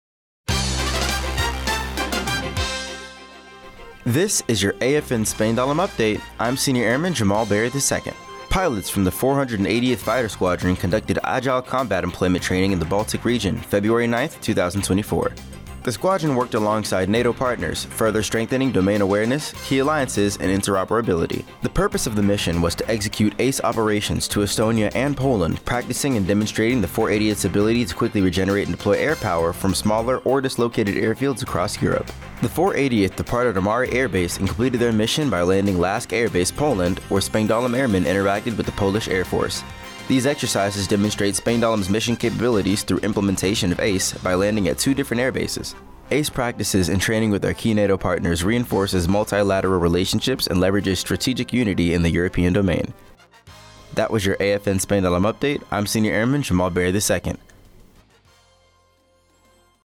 Radio news.